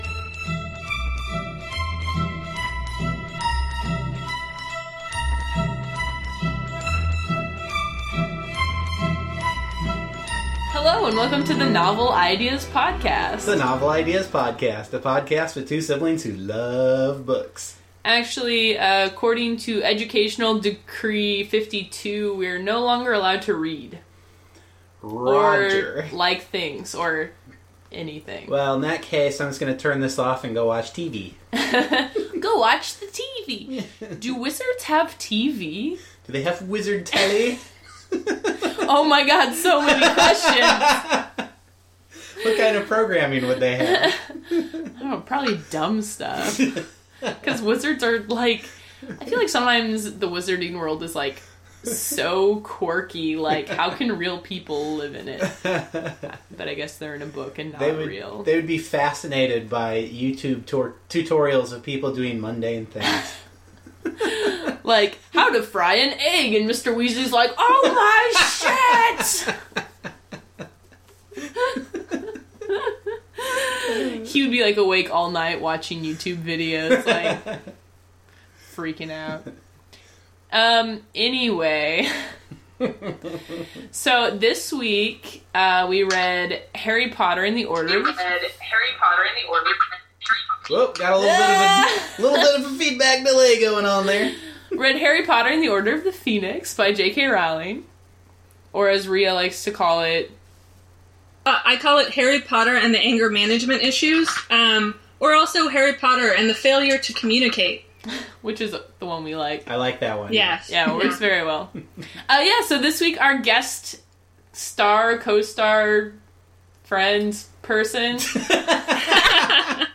Our guest star for this episode was recorded over Skype, so there are occasional echoes in the audio, but I think I managed to get enough of it out to not be distracting.